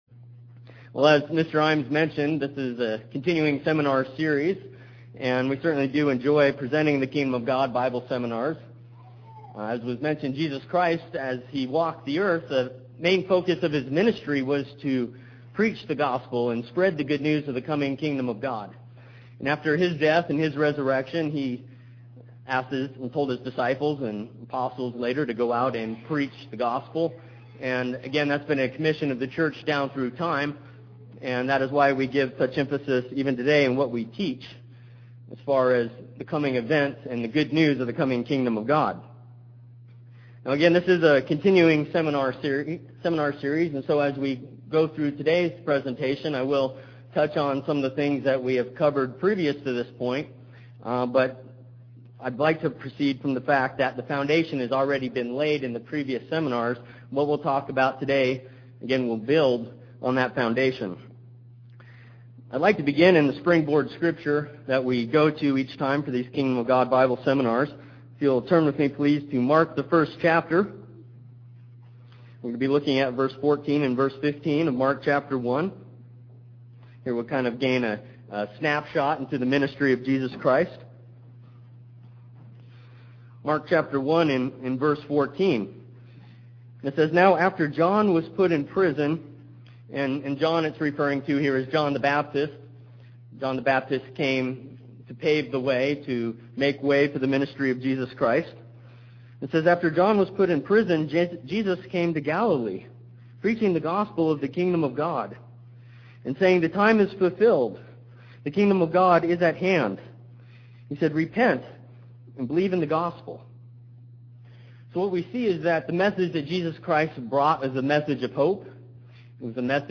Faith is demonstrated by our works. This message was given for a Kingdom of God seminar.